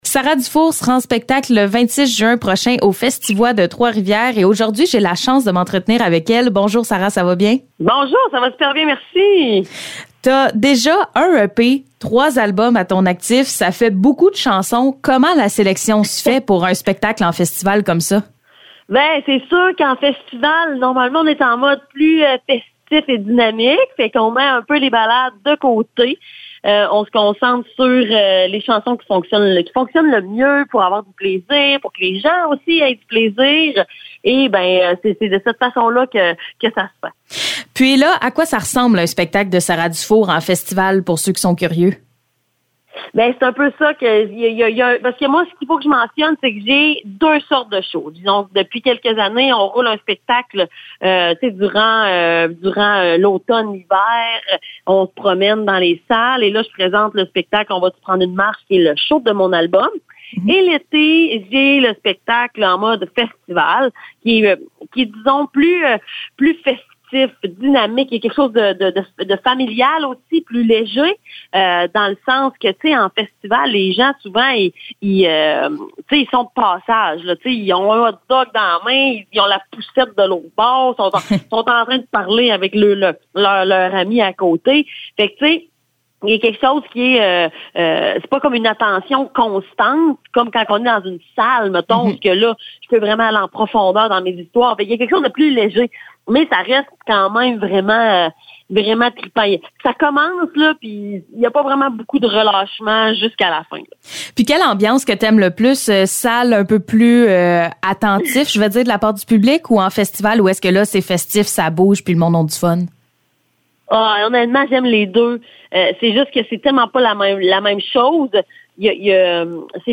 Entrevue avec Sara Dufour